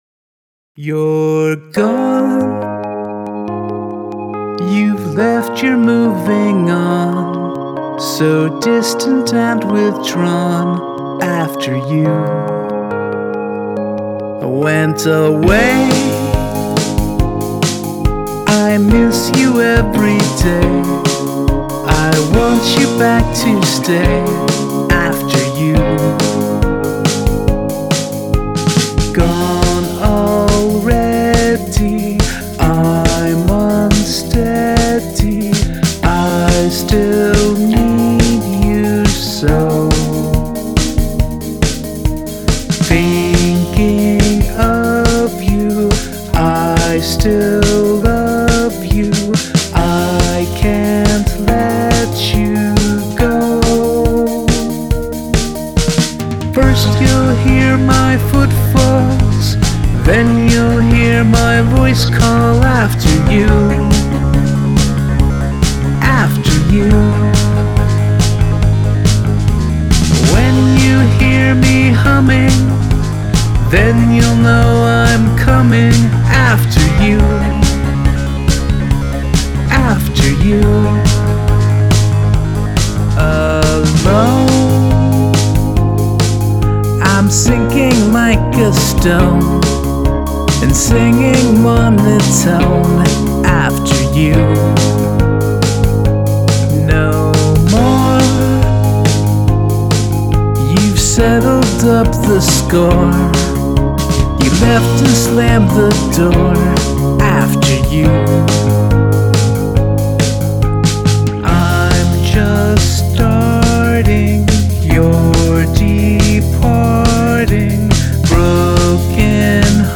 Counter Melody
I like the drum sound a lot.